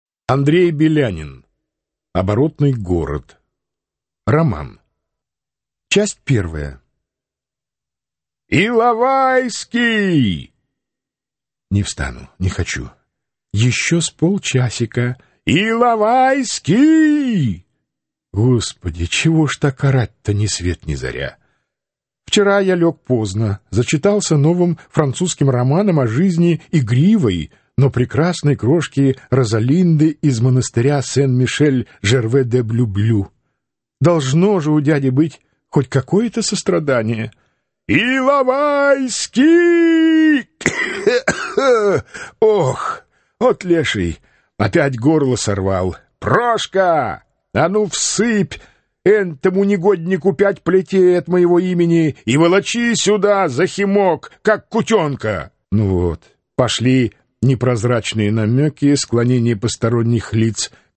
Аудиокнига Оборотный город | Библиотека аудиокниг